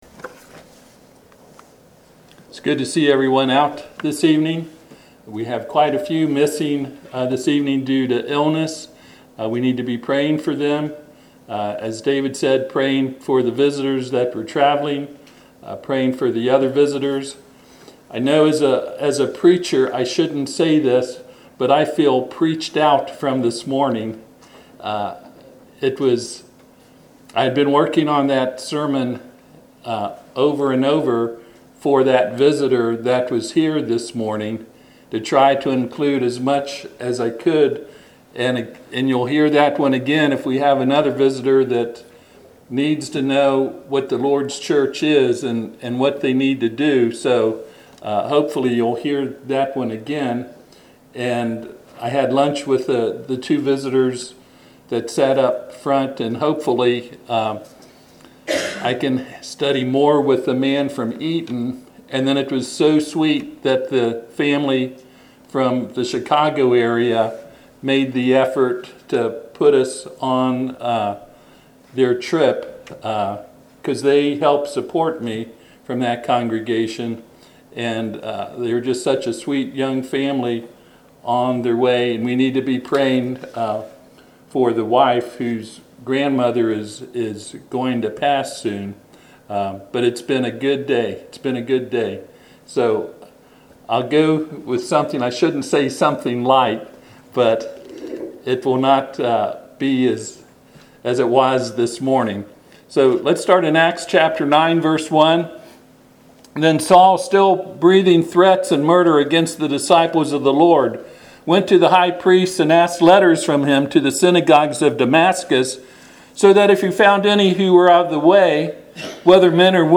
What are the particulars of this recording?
Acts 9:6 Service Type: Sunday PM https